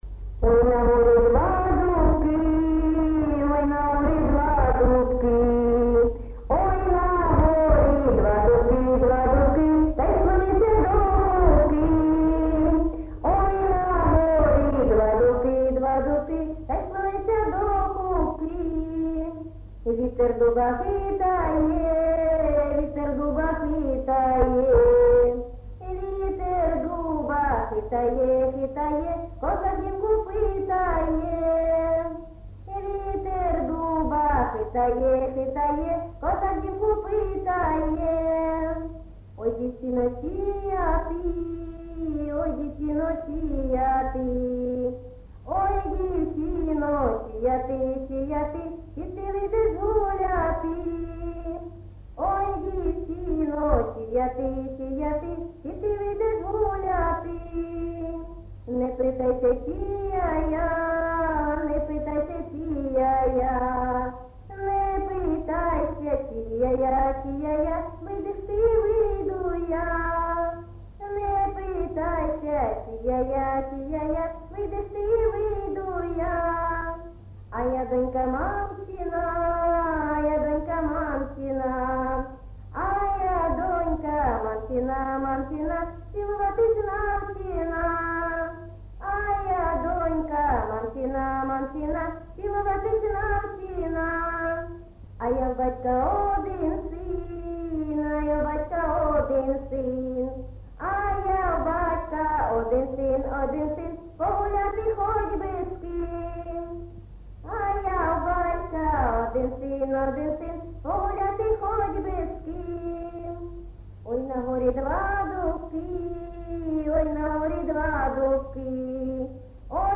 ЖанрПісні з особистого та родинного життя, Пісні літературного походження
Місце записус. Золотарівка, Сіверськодонецький район, Луганська обл., Україна, Слобожанщина